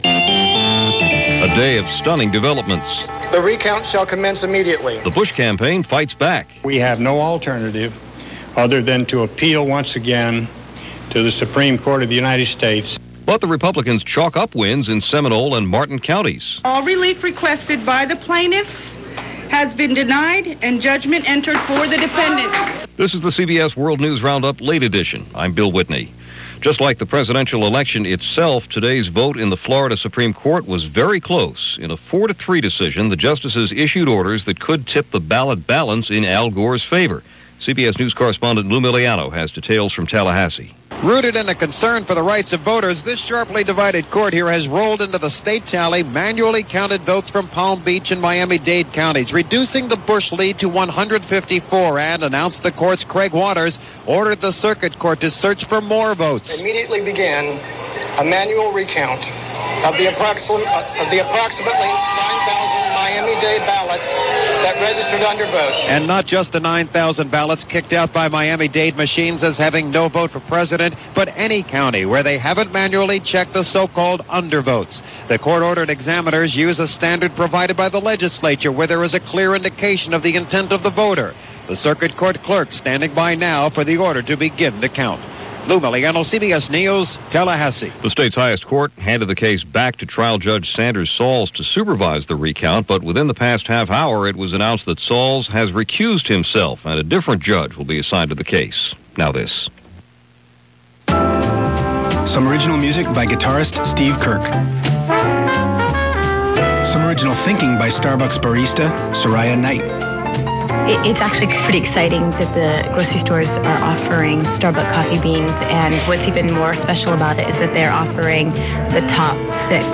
All that and a lot more, but the preoccupation for the country on this December 8, 2000 was the election as reported by The CBS World News Roundup: late Edition.